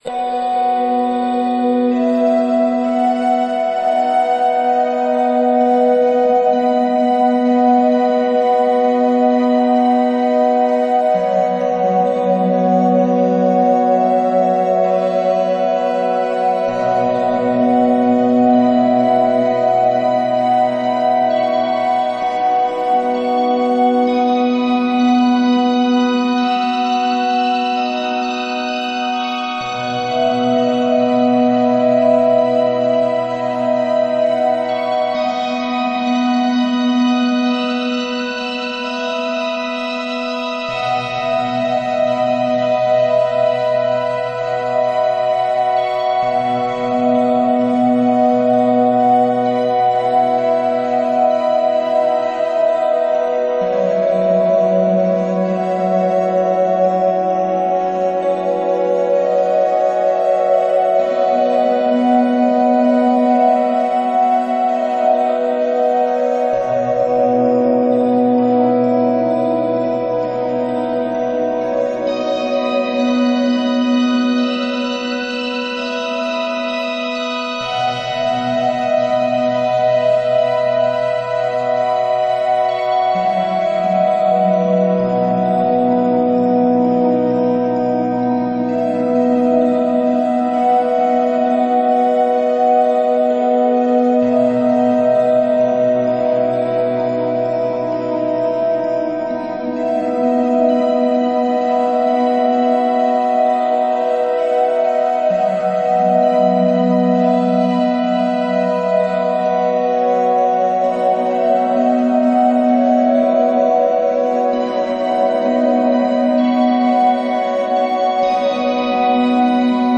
Exhibition. Instrumental written to accompany some pictures of a science fiction exhibition that was running at Kelvingrove Museum, Glasgow, over Christmas and the New Year 2009-2010.